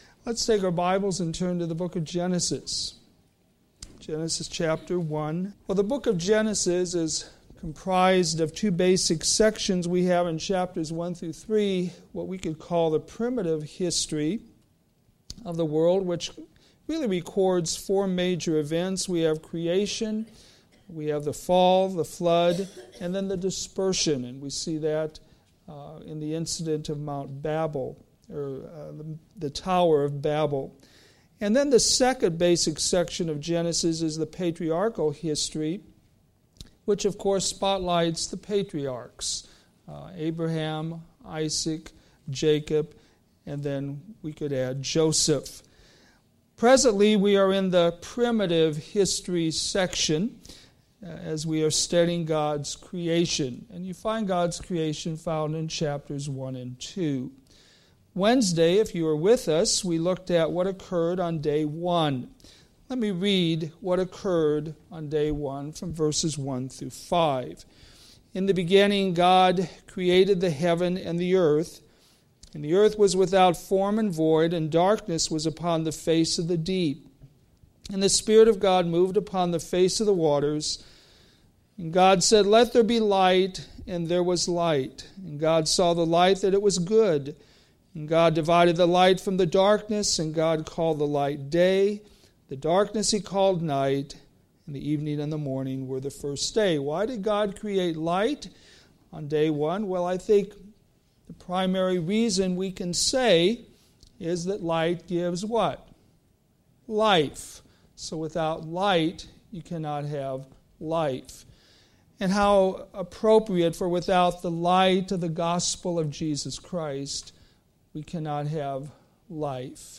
Sermons based on Old Testament Scriptures